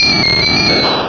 Cri de Fantominus dans Pokémon Rubis et Saphir.